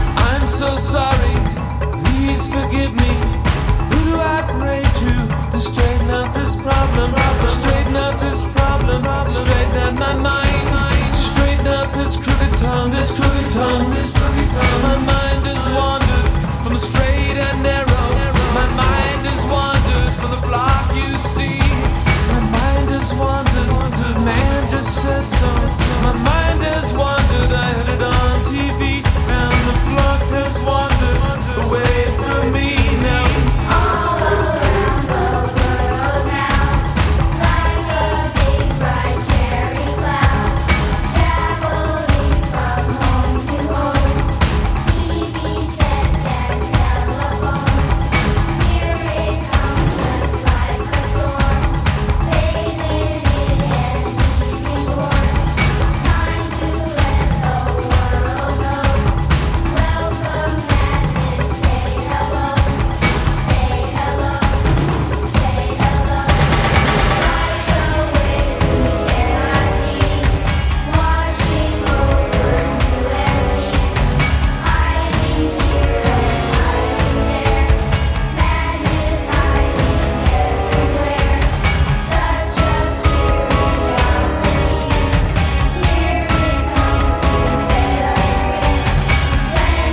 Alternative Rock, New Wave, Progressive Rock